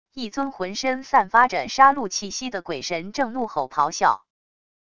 一尊浑身散发着杀戮气息的鬼神正怒吼咆哮wav音频